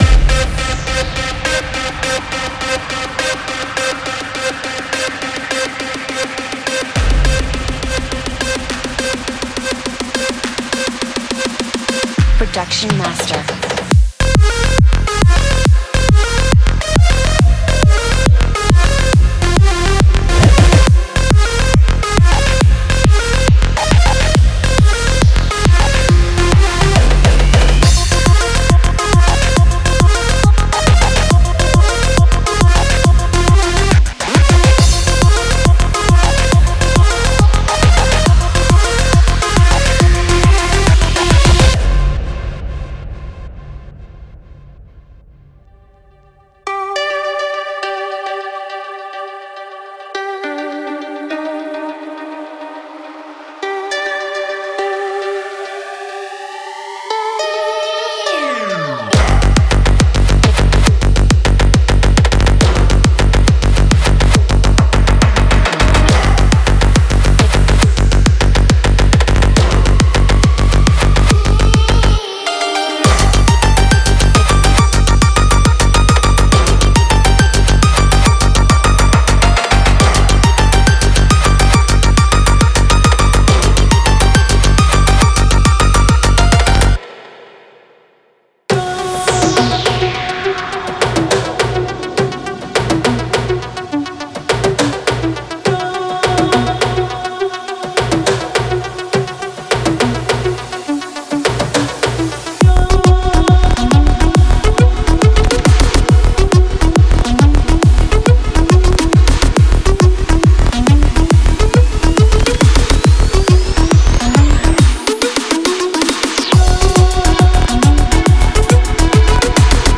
完全致力于您的Psytrance，Goa和Hard Trance作品。
使用各种硬件合成器和舷外处理器使每个声音都尽可能完整。
拳打脚踢，超紧的网罗和鼓掌，明亮的踩-，手工制作的打击乐器和怪异的毛刺样本，这些东西一应俱全。
•136个鼓和打击乐循环
•60个合成器循环